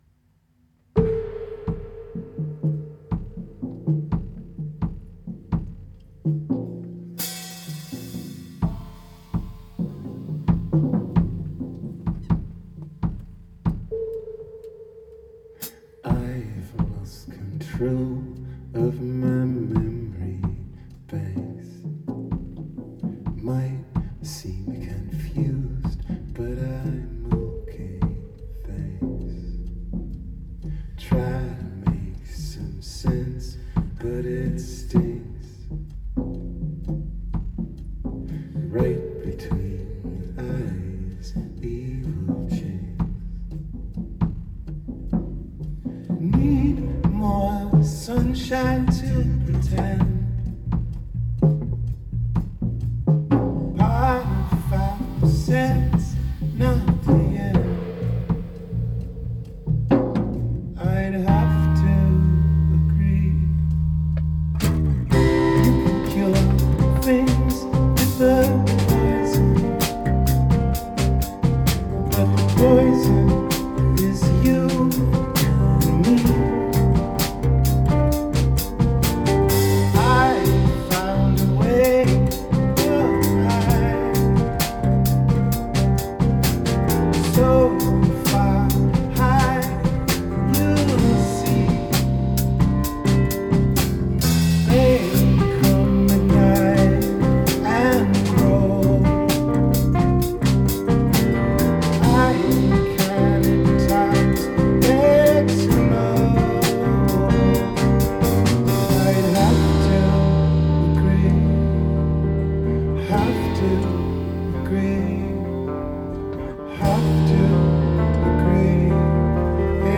Rehearsals 6.9.2013